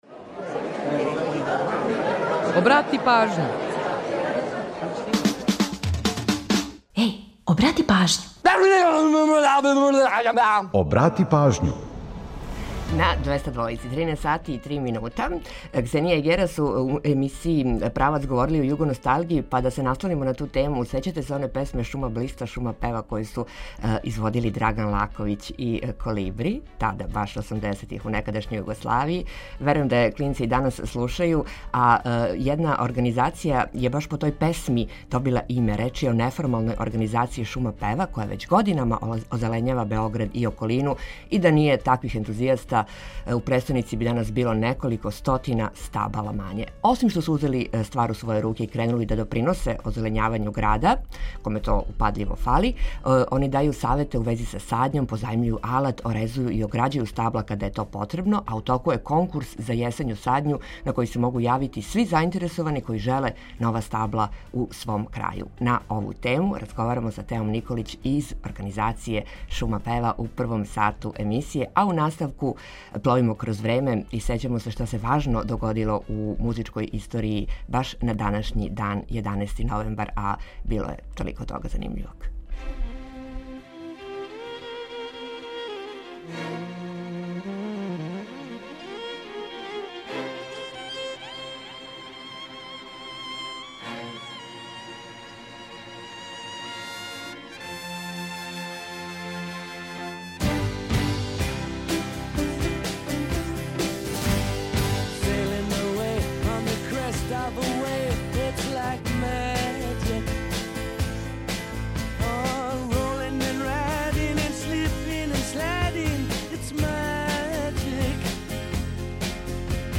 Чућете приче које стоје иза песама, новитете са светских топ листа и важне догађаје из света музике који су обележили данашњи датум.